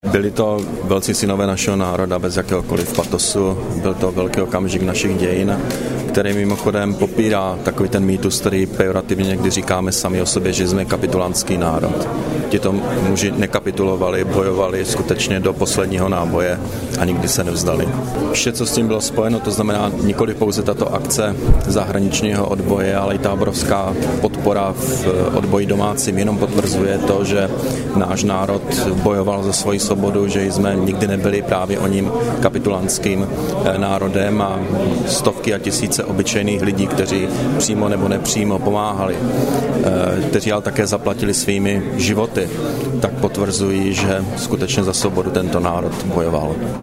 Premiér Petr Nečas při pietní vzpomínce u příležitosti 70. výročí hrdinného boje a úmrtí československých parašutistů, 18. června 2012